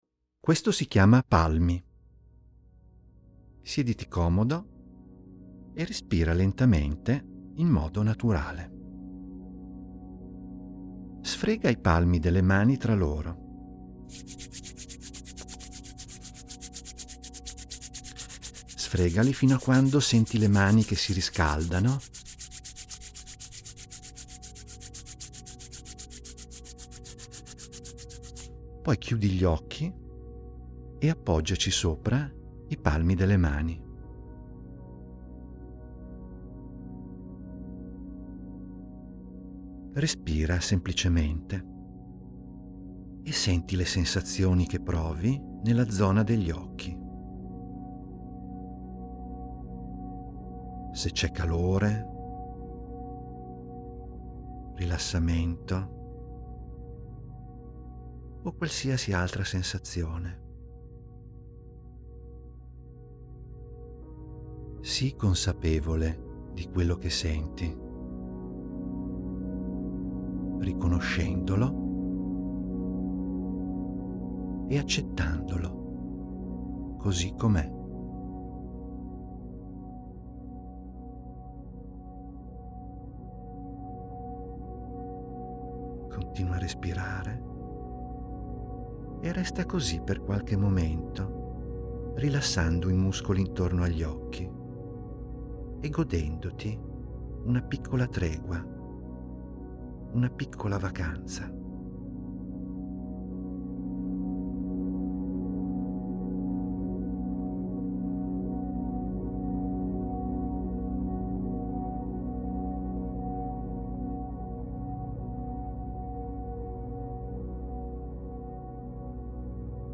In questa sezione trovi degli audio che ti guidano a svolgere delle mini-meditazioni di due minuti.